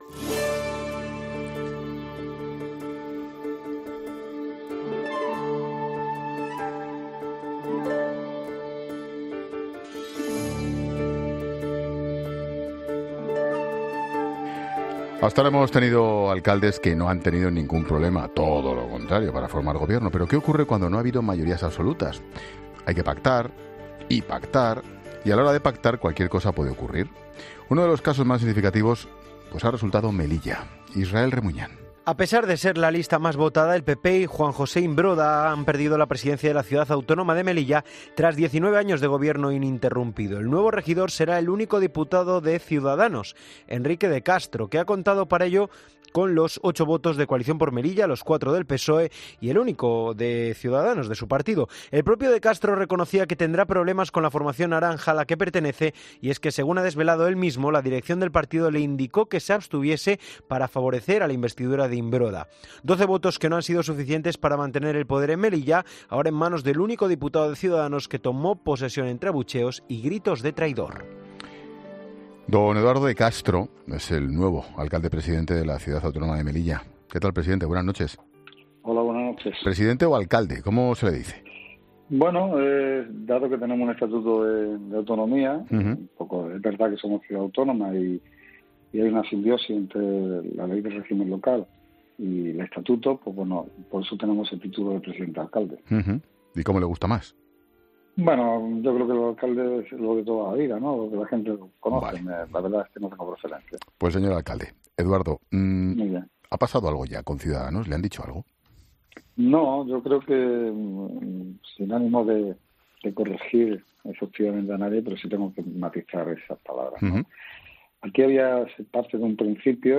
En una entrevista en La Linterna de COPE, De Castro ha explicado la razón por la que decidió presentarse finalmente a la investidura en lugar de apoyar a Imbroda.